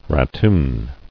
[ra·toon]